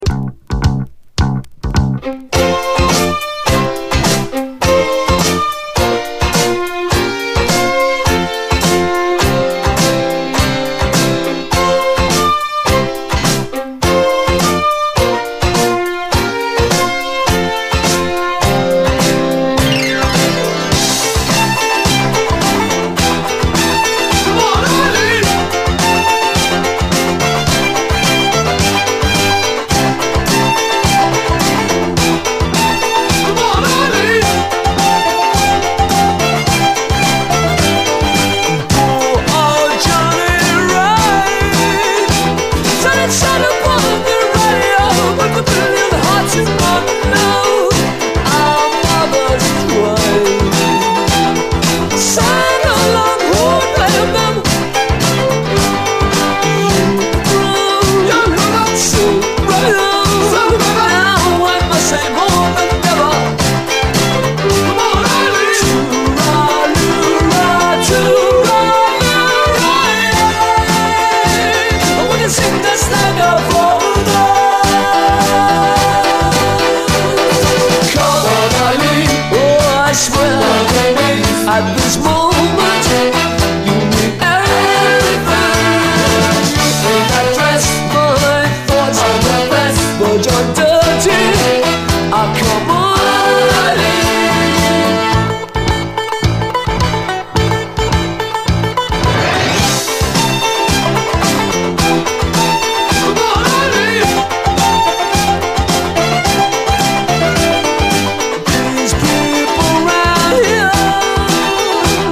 絶妙にライトなニューウェーヴ感と、UKラヴァーズ的な甘酸っぱさ。
チープなドラム・マシン上でスカスカに展開するガーリー・ニューウェーヴ・ファンク
ダビーなディレイ処理とピアノの配し方がオシャレです。